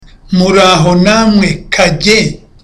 Dialogue: To the tailor